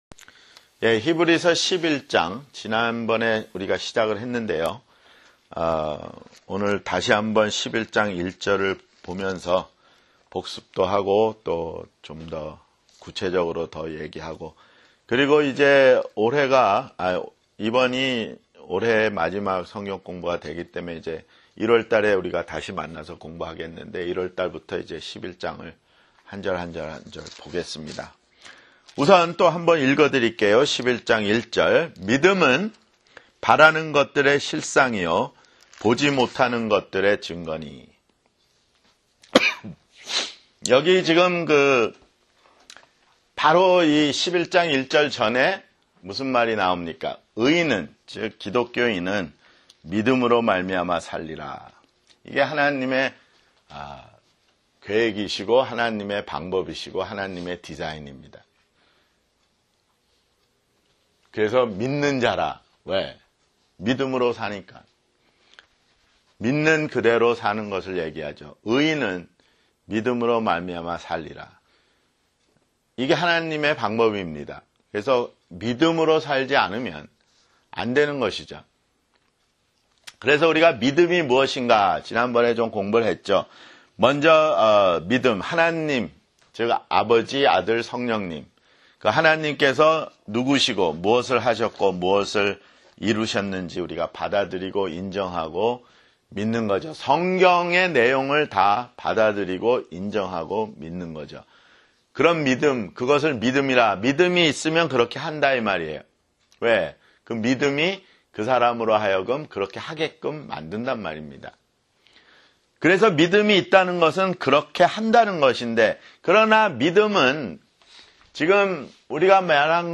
[성경공부] 히브리서 (37)